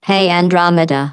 synthetic-wakewords
ovos-tts-plugin-deepponies_GLaDOS_en.wav